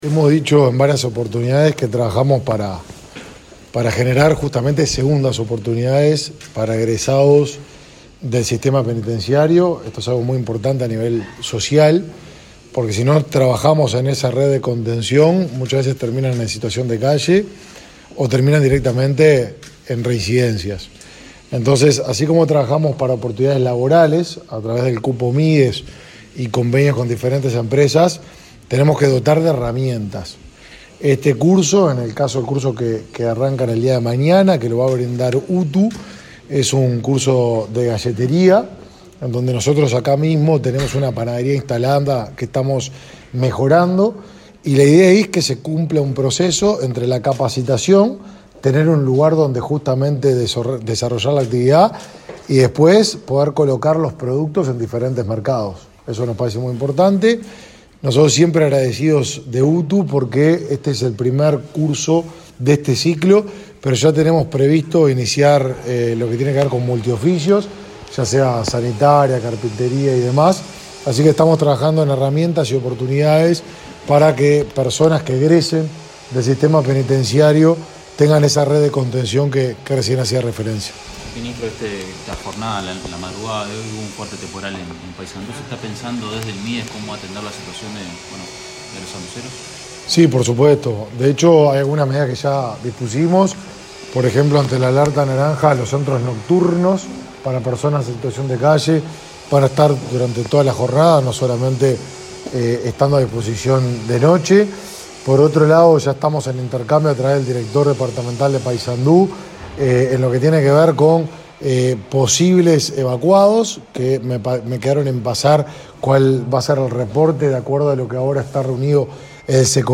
Declaraciones a la prensa del ministro de Desarrollo Social, Martín Lema
El ministro Martín Lema dialogó con la prensa sobre la importancia de estas instancias.